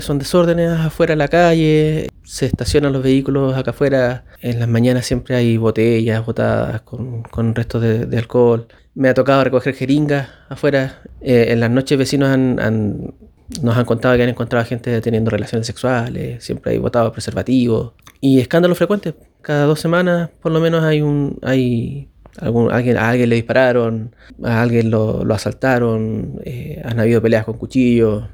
Un joven que trabaja en las cercanías del local ilegal, mencionó que llevan mucho tiempo soportando estas situaciones.